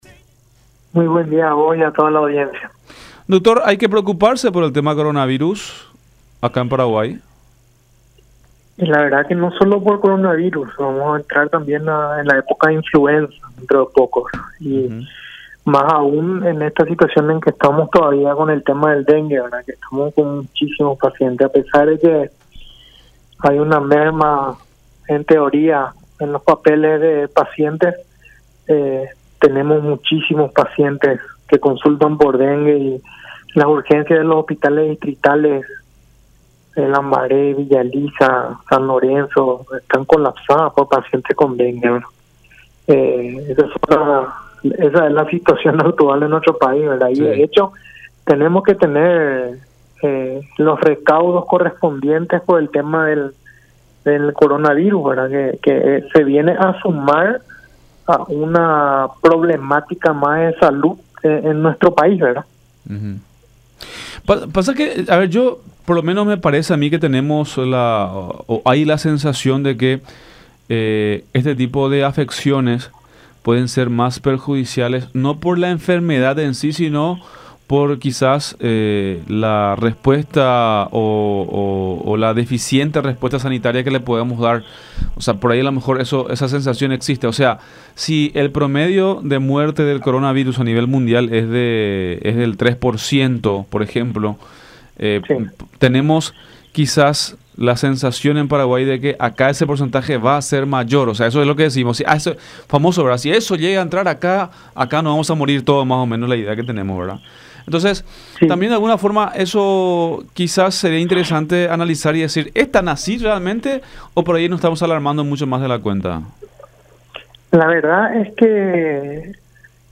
La mayoría de los hospitales siguen con un alto número de pacientes por casos sospechosos de por la enfermedad causada por el mosquito Aedes Aegypti”, explicó Morínigo en diálogo con La Unión, por lo cual considera que se debe empezar desde el principio, ya desde las escuelas, la enseñanza sobre la prevención de origen y propagación de estos males.